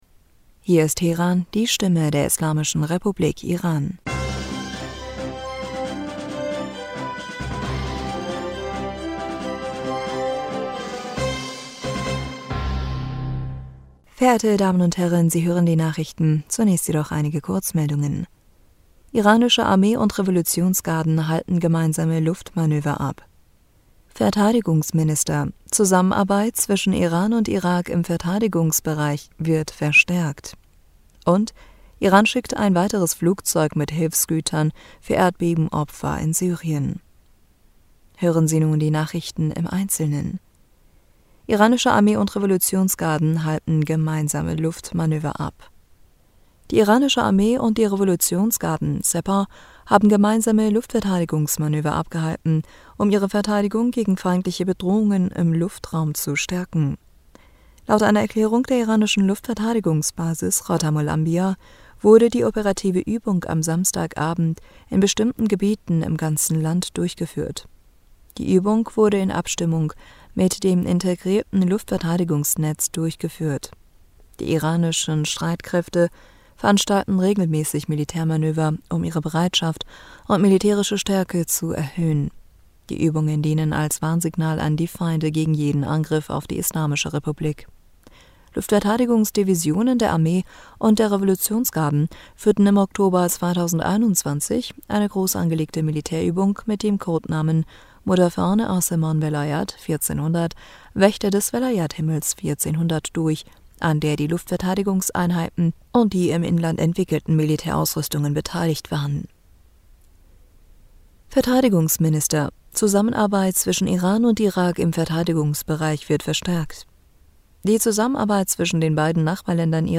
Die Nachrichten von Sonntag, dem 26. Februar 2023